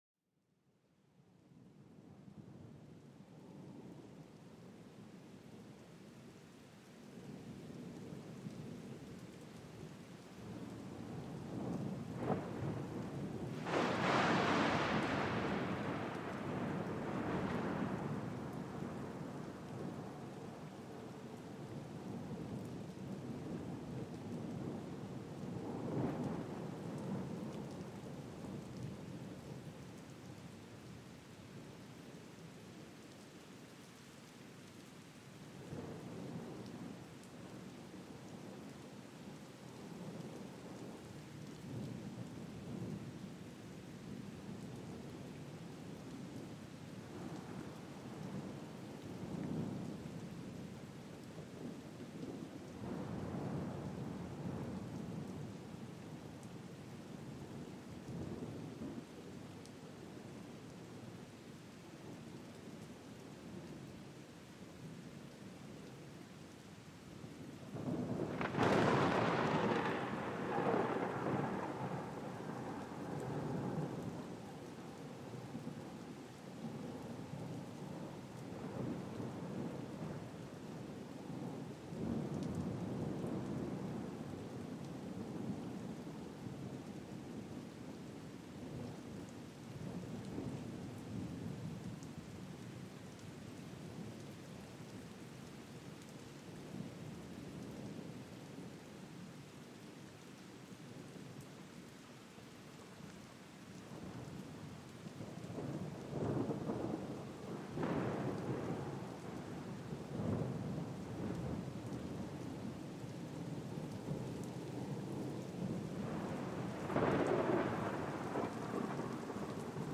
Canto de la tormenta y la lluvia: calma tu mente bajo un cielo tormentoso
Déjate envolver por el retumbar lejano de los truenos y la melodía suave de la lluvia cayendo. Cada gota y trueno crea una atmósfera perfecta para liberar tensiones y calmar la mente.